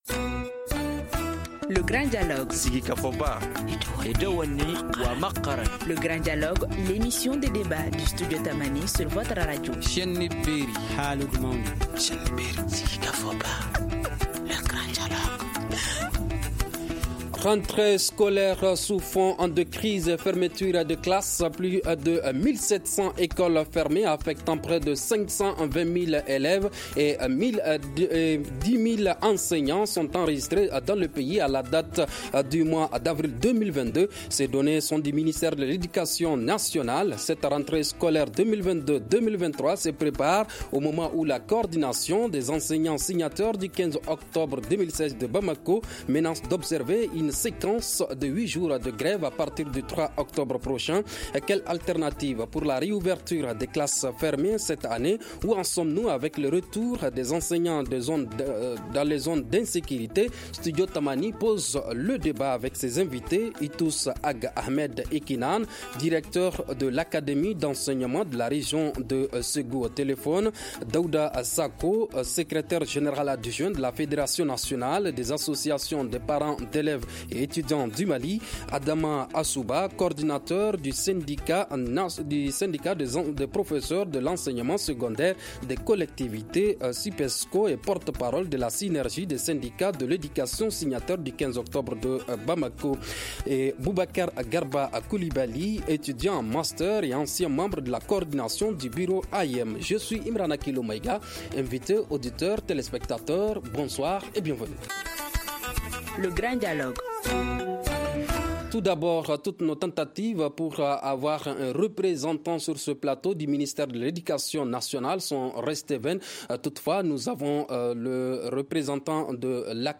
Studio Tamani pose le débat avec ses invités :